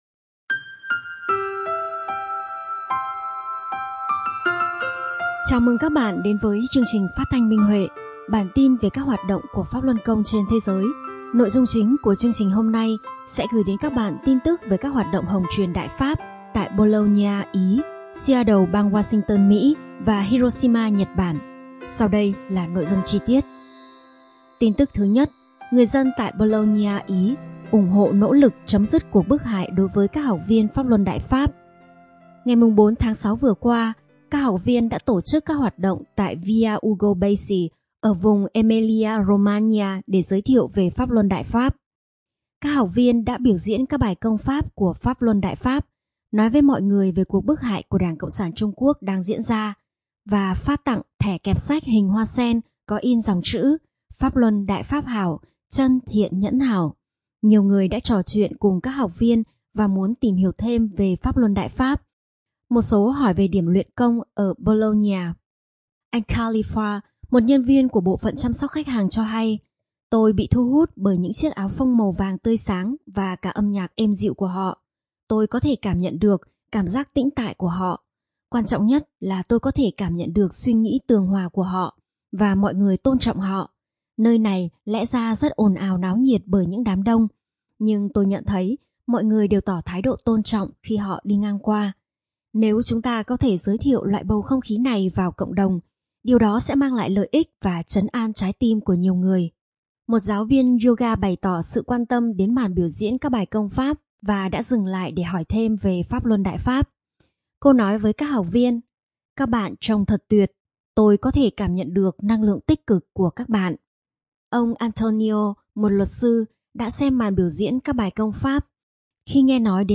Chương trình phát thanh số 38: Tin tức Pháp Luân Đại Pháp trên thế giới – Ngày 19/6/2023